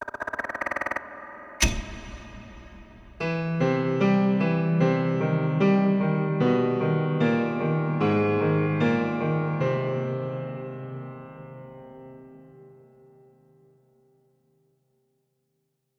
WinFX.wav